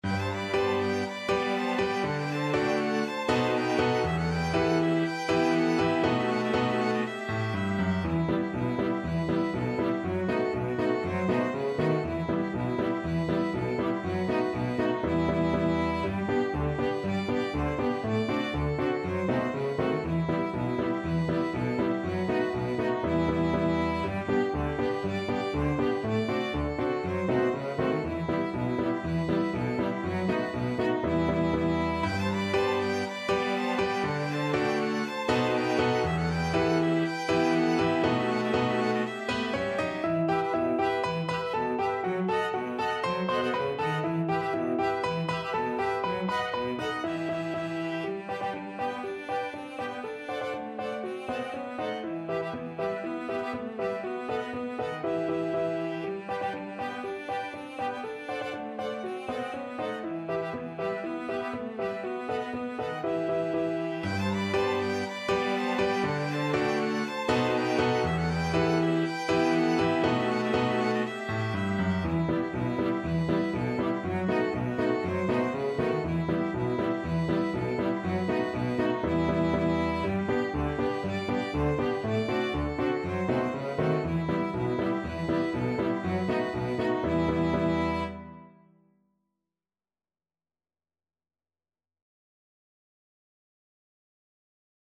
Piano Quintet version
Violin 1Violin 2ViolaCelloPiano
E minor (Sounding Pitch) (View more E minor Music for Piano Quintet )
4/4 (View more 4/4 Music)
Allegro (View more music marked Allegro)
Traditional (View more Traditional Piano Quintet Music)
world (View more world Piano Quintet Music)
Ukrainian